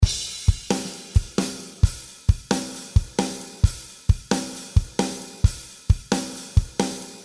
133 BPM - Fearless (21 variations)
With hard open hihat loops, snare fill and tom fills, 4 loops with ride cymbals.
Qty: $0.00 21 loops in heavy metal style.